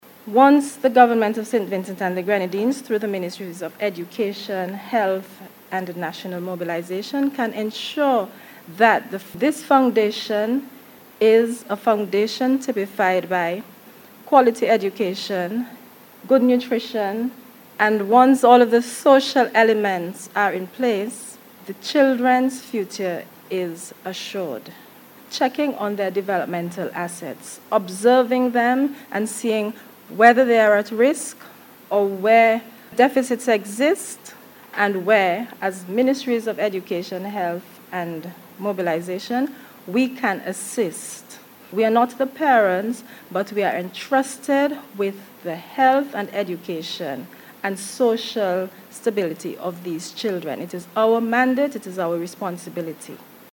Miss Gilchrist made the point, as she addressed participants at the start of workshop on Early Childhood Education on Tuesday June 9th.